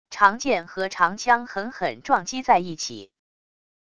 长剑和长枪狠狠撞击在一起wav下载